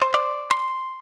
shamisen_cdc1.ogg